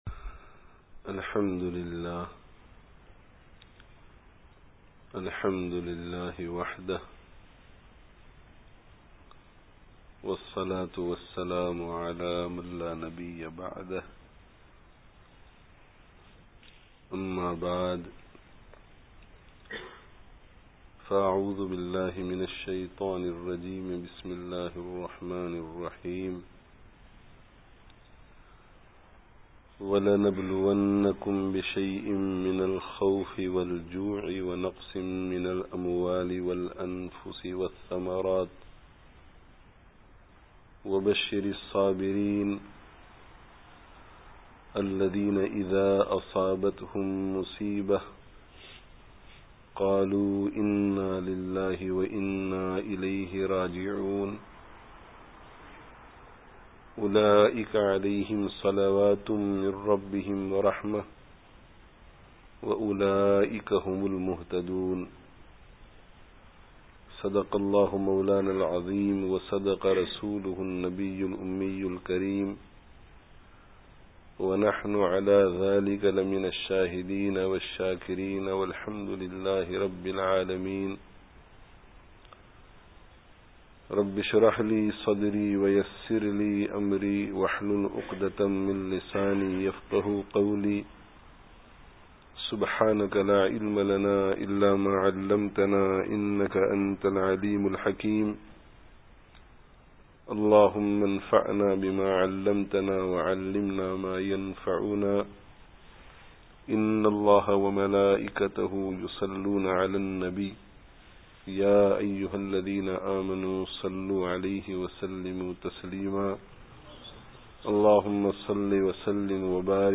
friday tazkiyah gathering